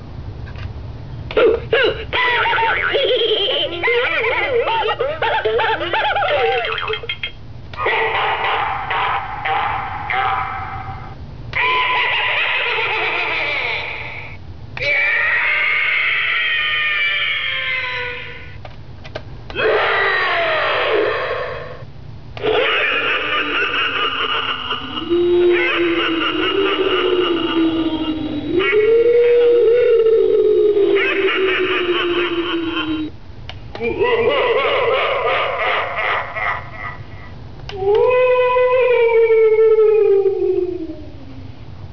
.WAV sound allowing you to hear the product.
Loud enough to be heard yet not so loud so as to potentially damage young ears
Eight spooky sound effects - good, given how inexpensive the product is